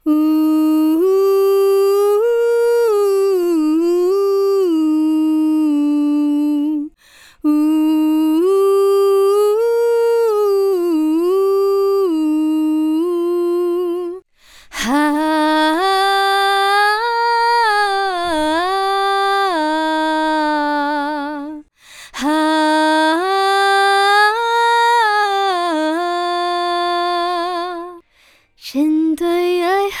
干声试听 下载通道